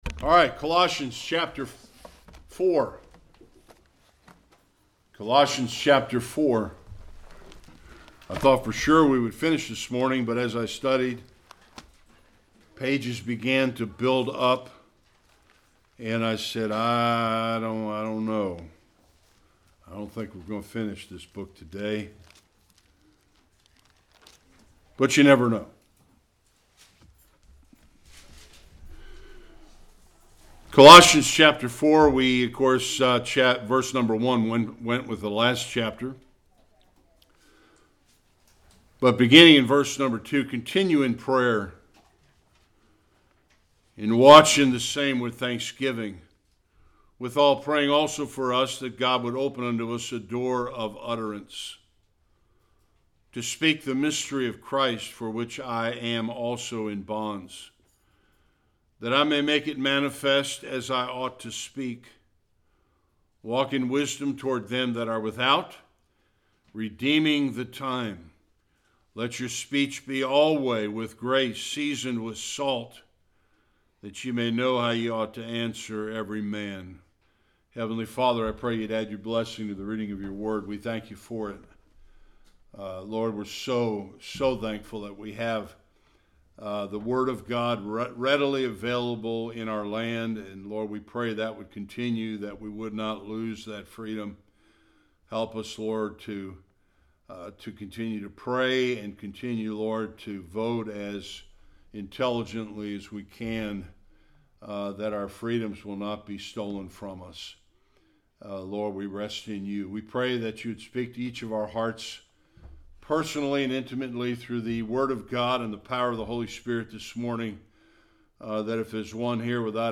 2-3a Service Type: Sunday Worship Prayer is one of the most critical uses of the gift of speech.